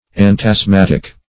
antasthmatic \ant`asth*mat"ic\ ([a^]nt`[a^]z*m[a^]t"[i^]k; see